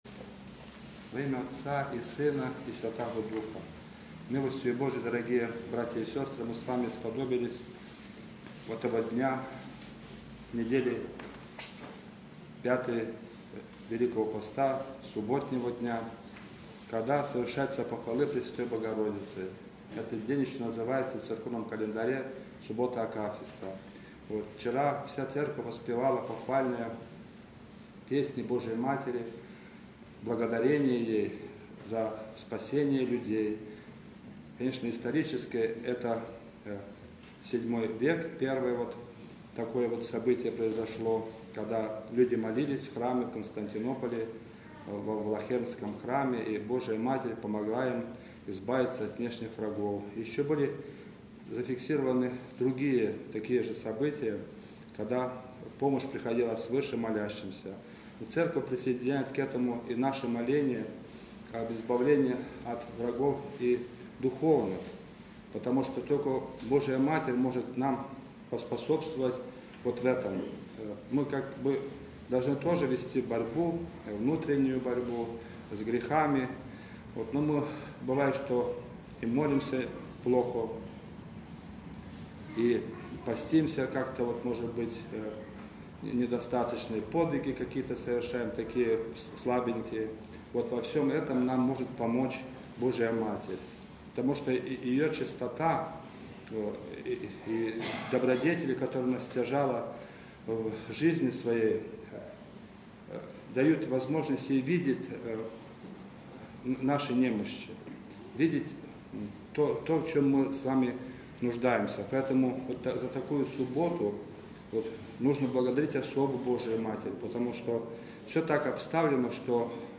Тропа́рь Похвалы́ Богоро́дицы, глас 8
хор духовенства храма, любительский хор храма
профессиональный хор